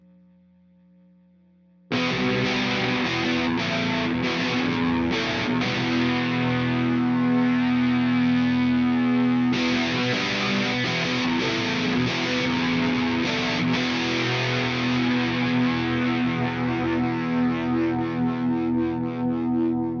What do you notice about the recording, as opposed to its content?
Here are a few clips BEFORE i took the pups out. Realize these were done w/ a line 6 guitar port (which I no longer have) so I wont be able to replicate this on the back end exactly, but I'll do my best!